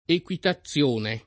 [ ek U ita ZZL1 ne ]